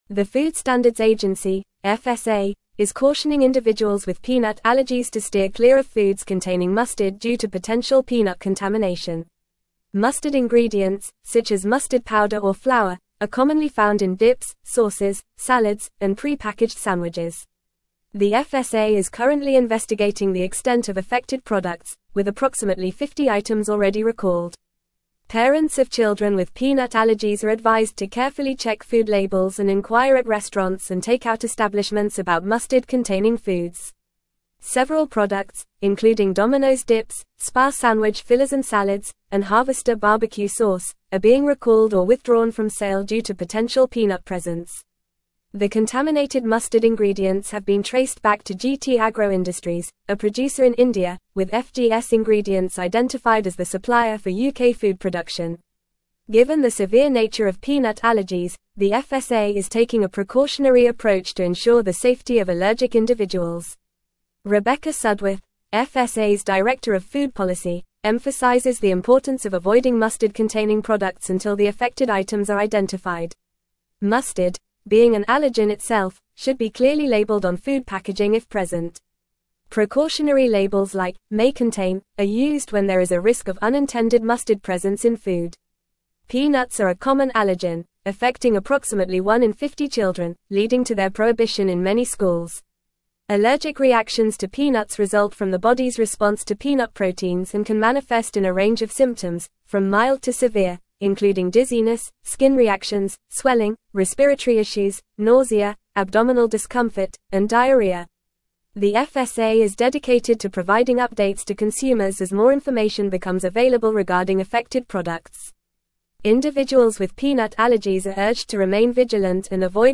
Fast
English-Newsroom-Advanced-FAST-Reading-FSA-Warns-of-Peanut-Contamination-in-Mustard-Products.mp3